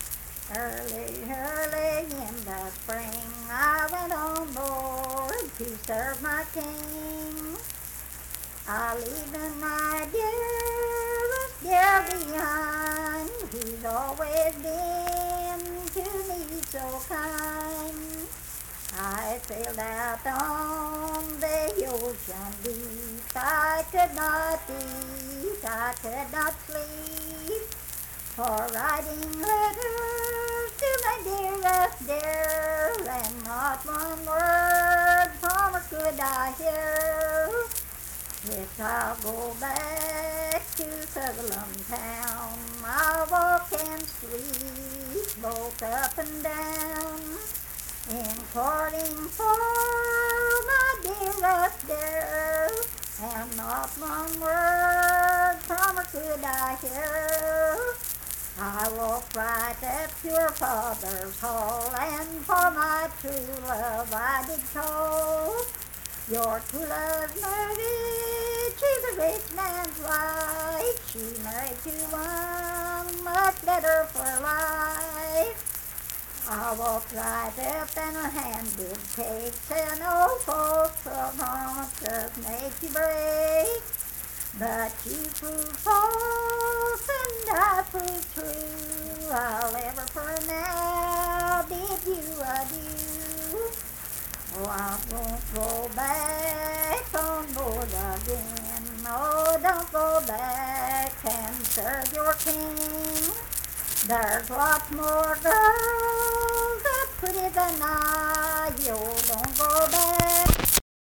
Unaccompanied vocal music performance
Verse-refrain 6(4).
Voice (sung)